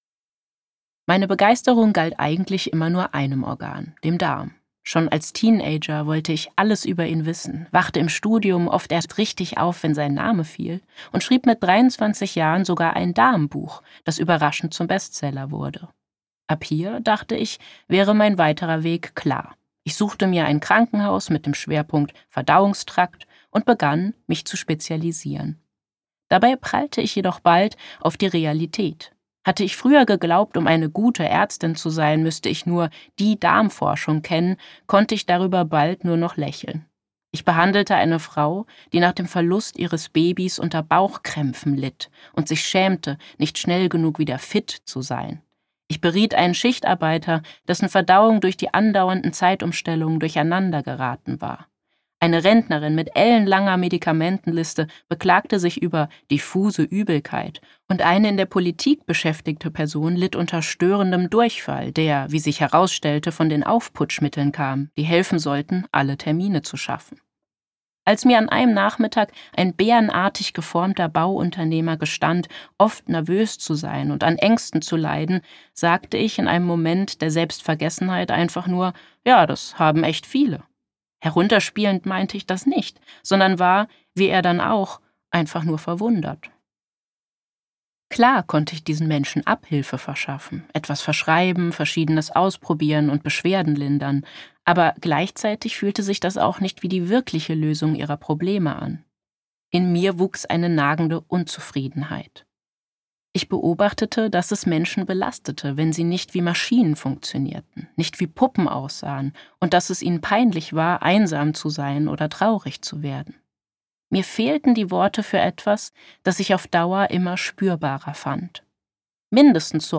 Details zum Hörbuch
Sprecher Giulia Enders